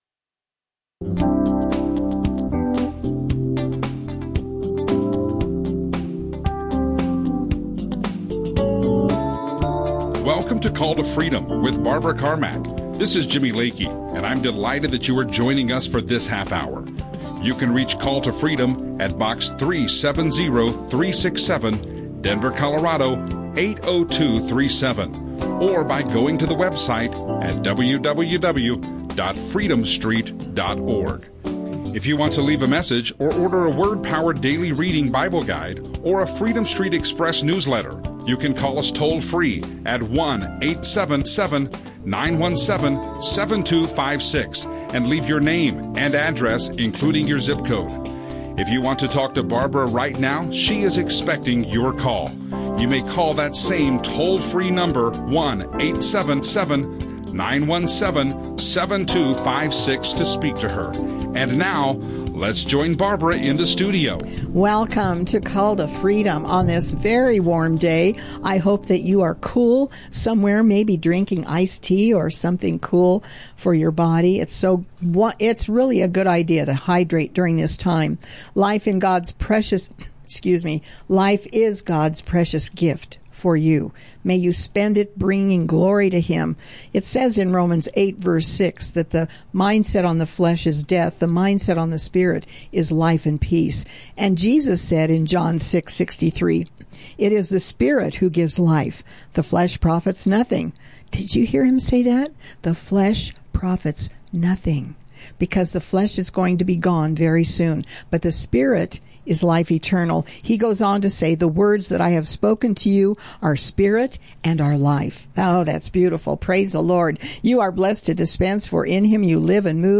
Christian talk